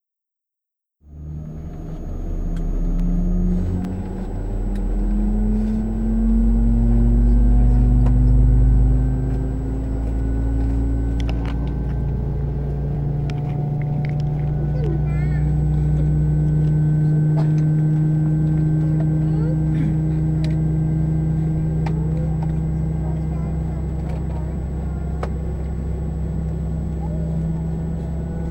v2500-start1.wav